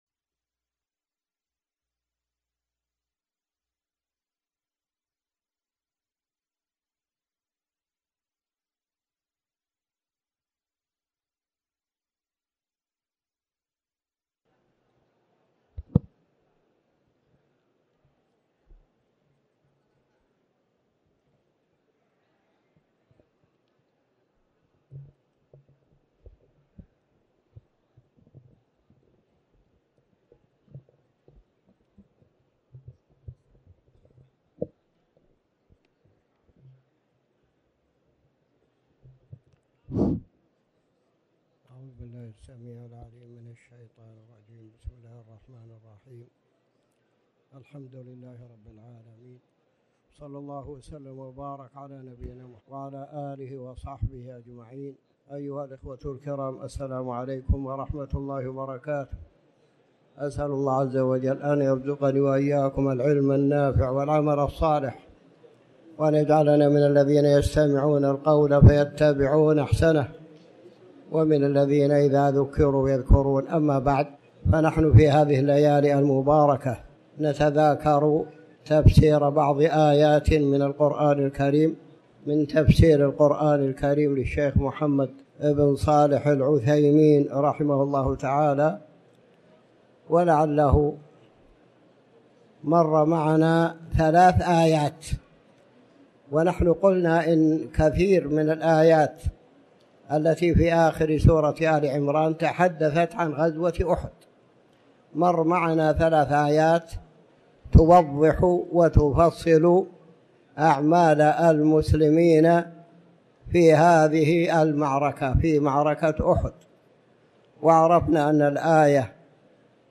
تاريخ النشر ٢٠ ربيع الأول ١٤٤٠ هـ المكان: المسجد الحرام الشيخ